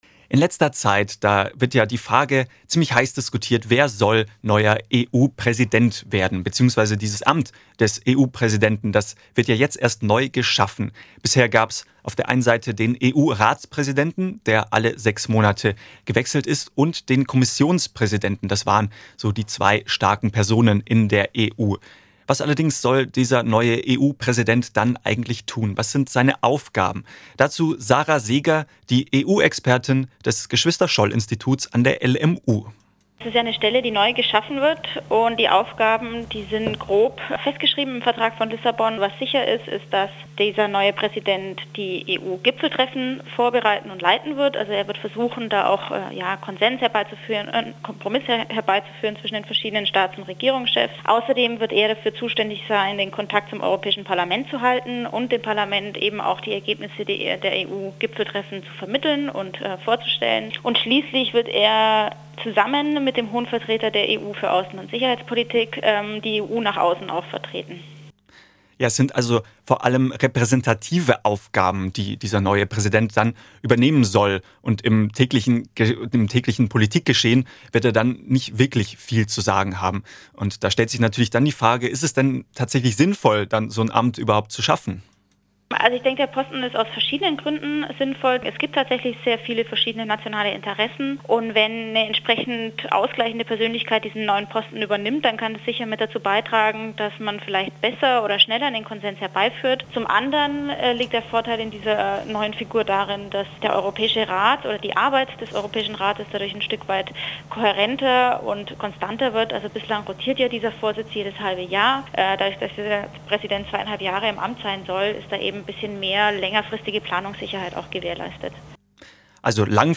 Aufgaben und Besetzung - Interview